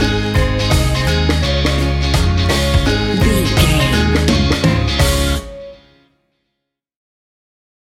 Uplifting
Ionian/Major
D♭
steelpan
drums
percussion
bass
brass
guitar